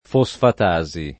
fosfatasi [ fo S fat #@ i ]